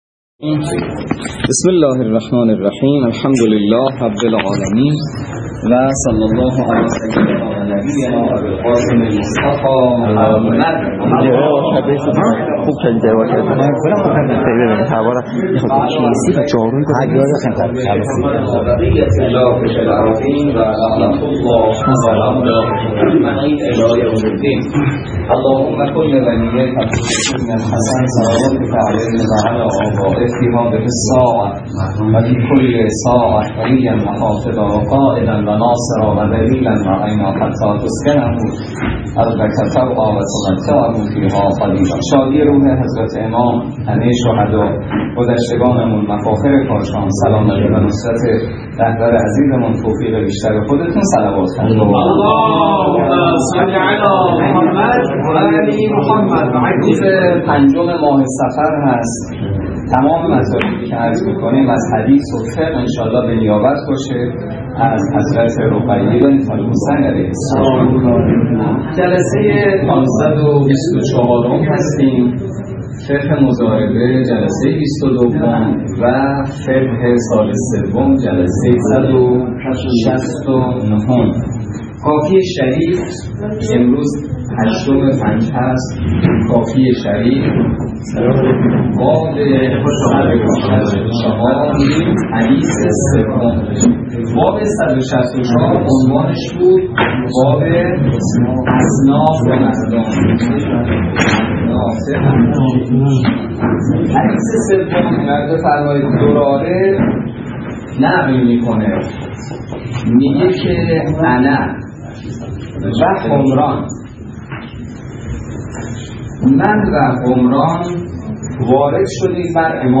روایات ابتدای درس فقه موضوع: فقه اجاره - جلسه ۲۲